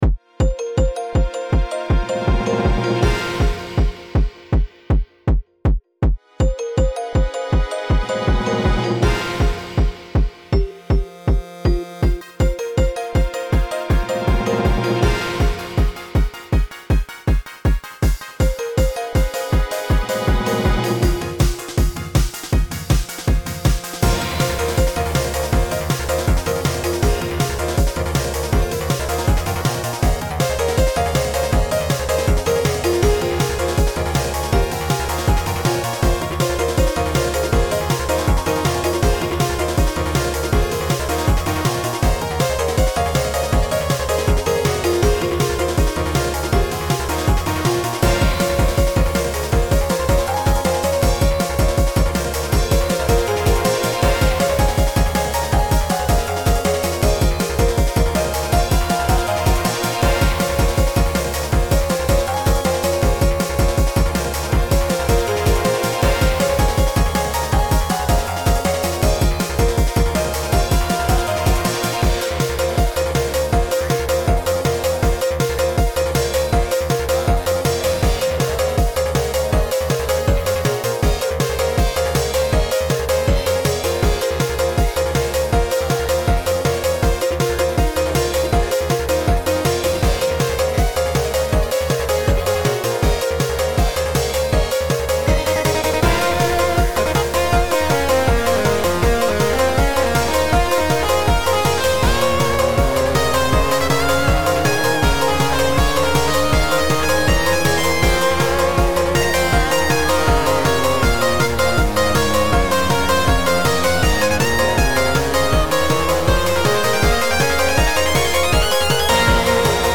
boss themes